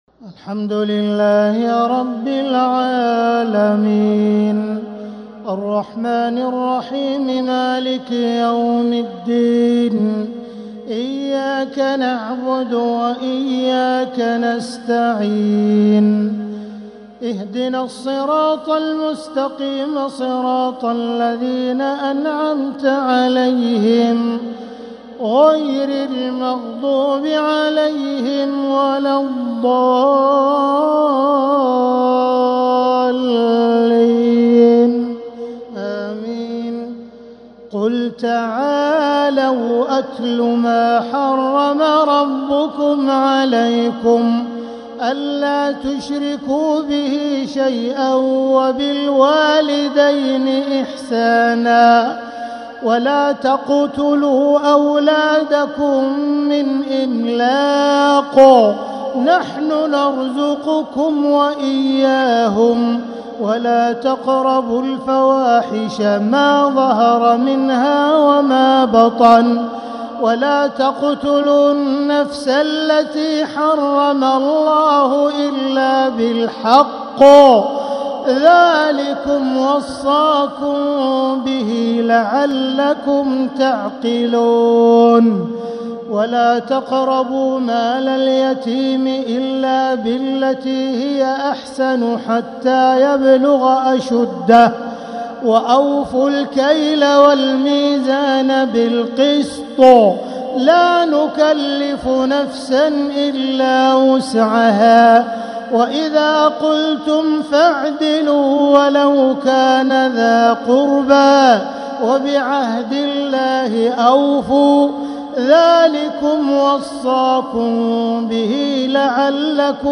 تراويح ليلة 10 رمضان 1446هـ من سورة الأنعام (151-165) | taraweeh 10th niqht ramadan1446H Surah Al-Anaam > تراويح الحرم المكي عام 1446 🕋 > التراويح - تلاوات الحرمين